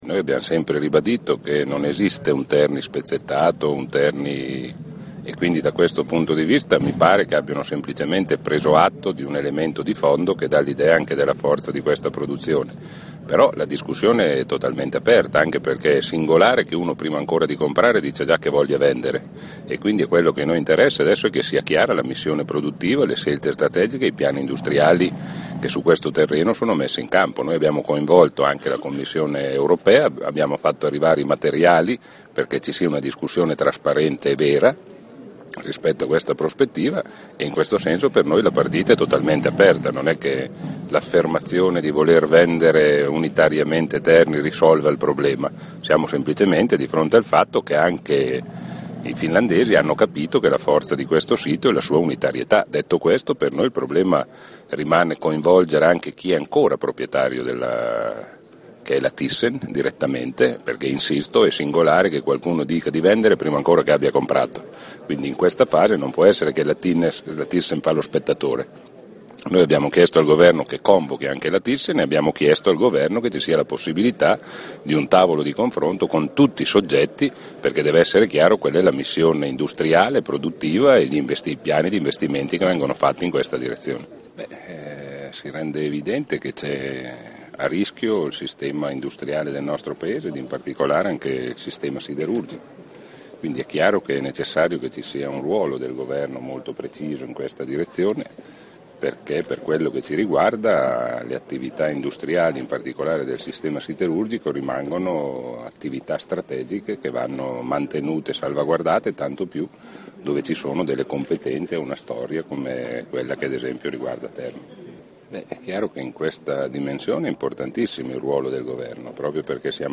Intervista a Maurizio Landini a Terni